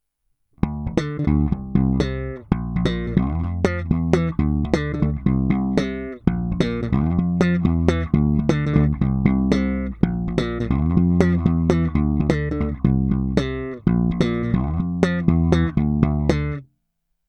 Následující nahrávky, není-li řečeno jinak, jsou provedeny rovnou do zvukové karty, jen normalizovány, jinak ponechány v původním stavu bez postprocesingu.
Slap